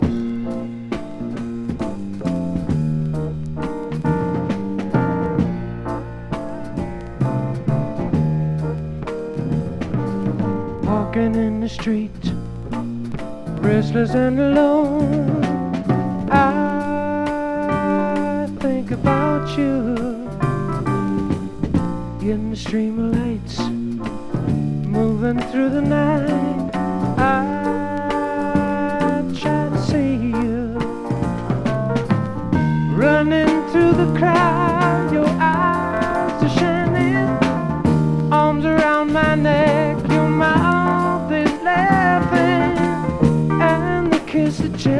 Rock, Pop　USA　12inchレコード　33rpm　Stereo